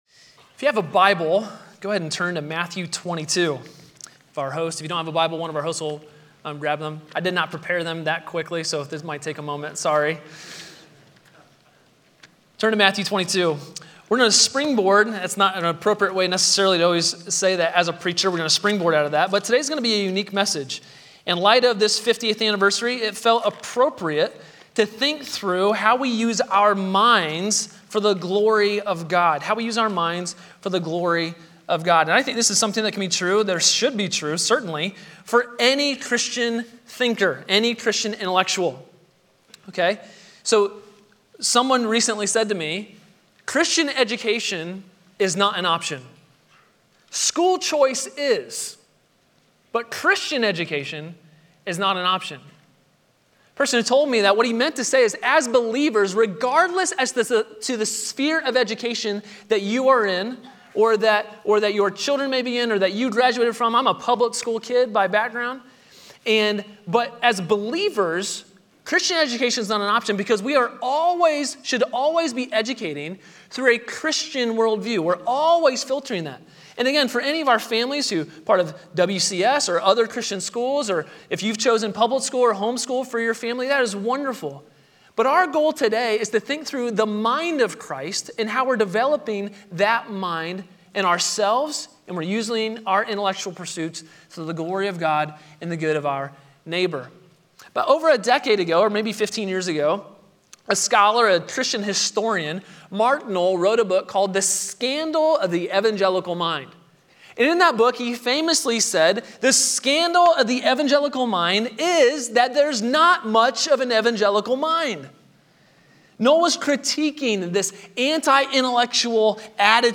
A sermon from the series "Standalone Sermons."